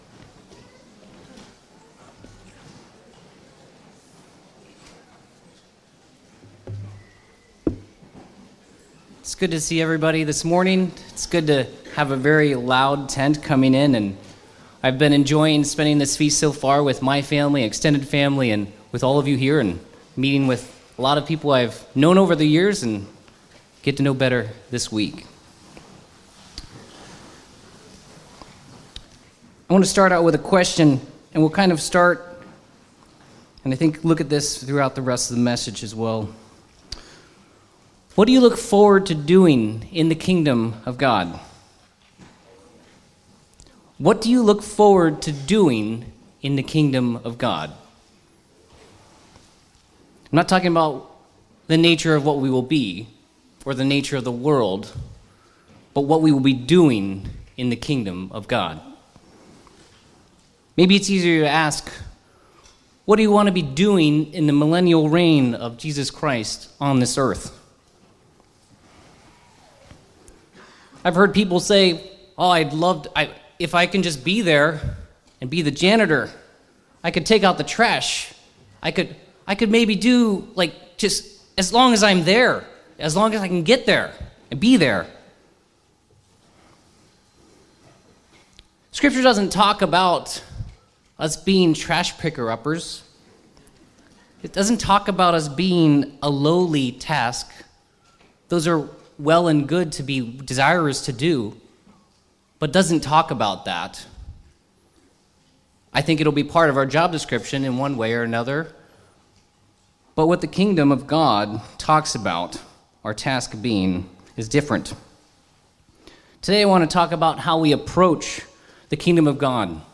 Sermons
Given in Walnut Creek, Ohio